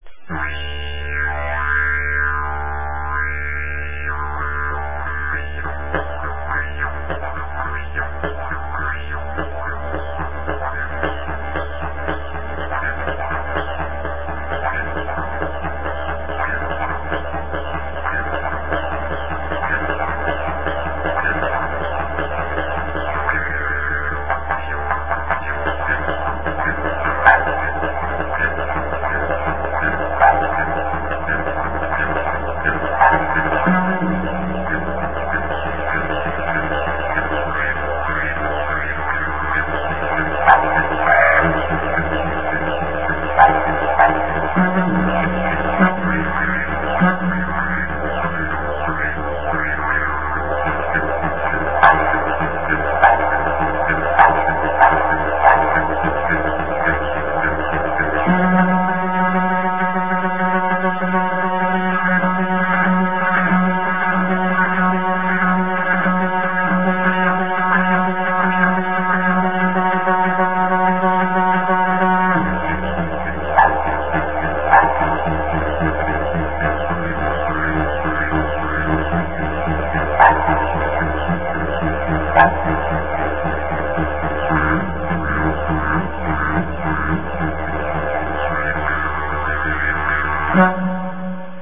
Non-traditional Didjeridu
Digitally recorded and mixed.